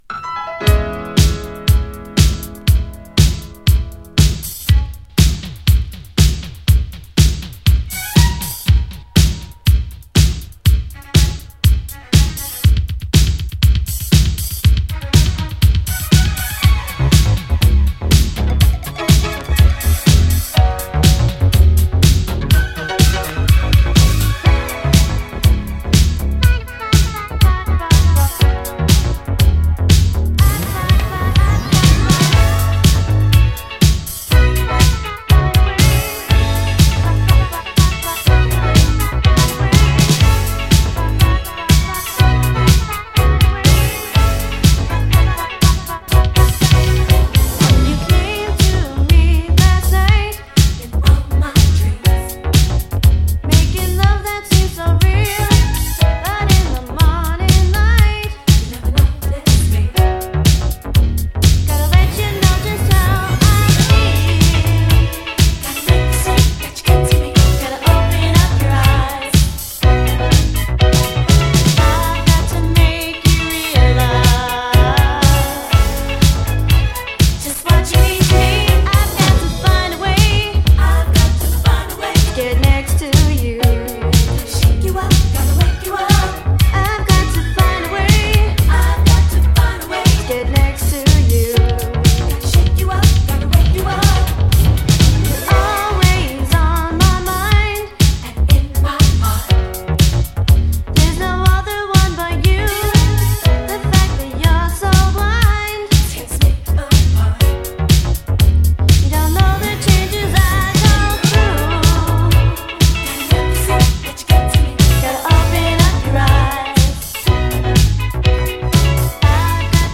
DISCO
MODERN BOOGIE〜80's DISCO CLASSIC !…